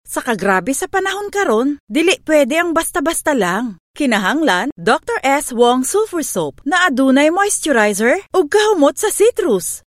CEBUANO FEMALE VOICES
female